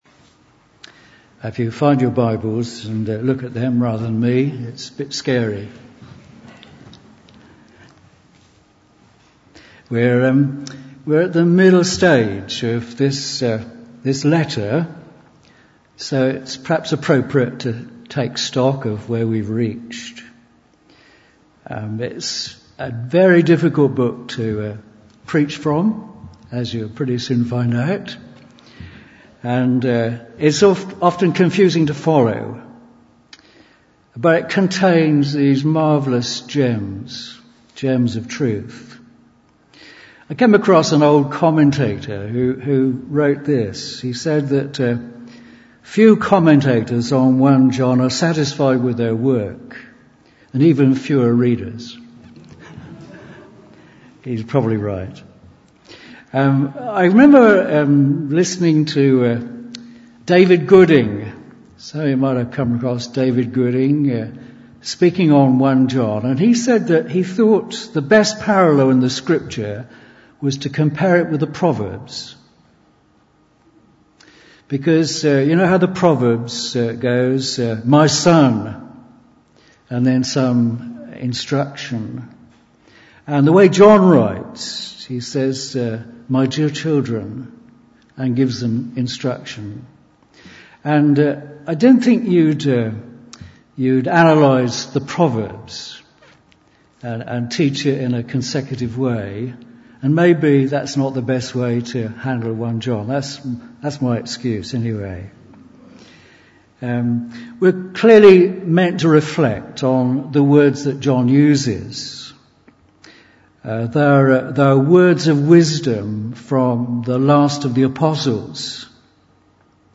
Bible Text: 1 John 2:28 – 3:10 | Preacher